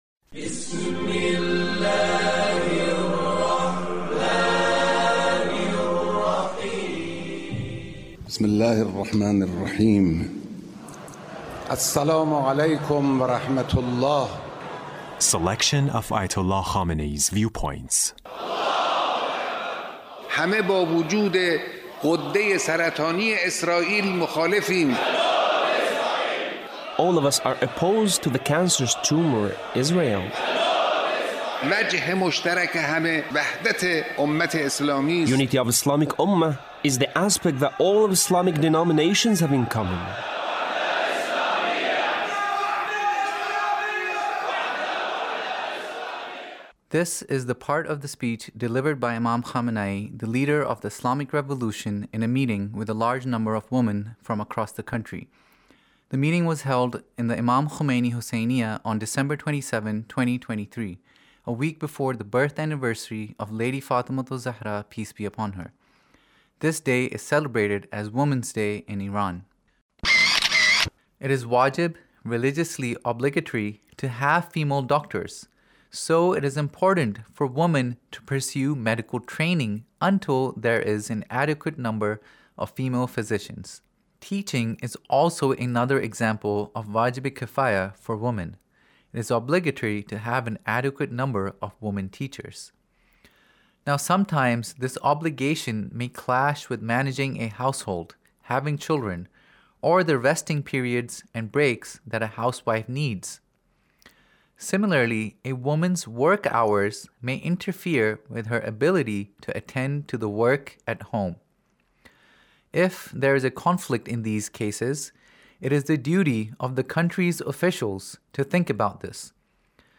Leader's Speech in a meeting with Ladies on Women's Day